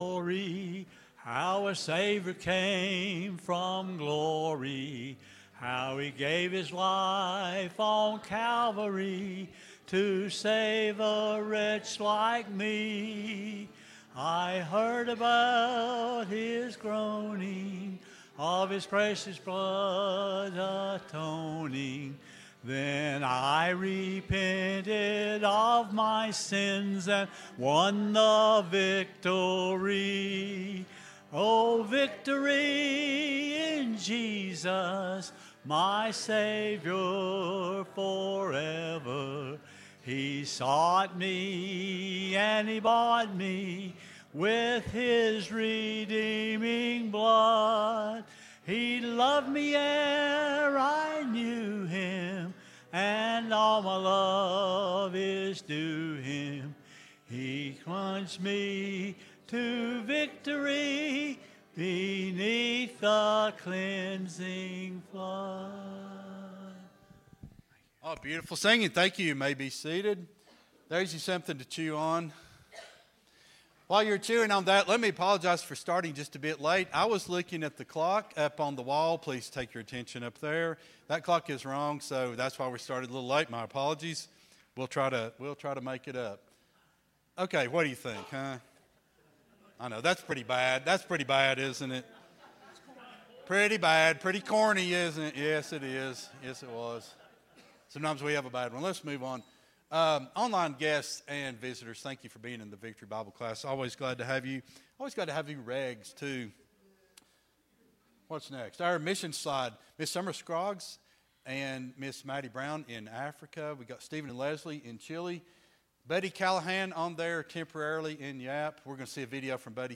02-16-25 Sunday School Lesson | Buffalo Ridge Baptist Church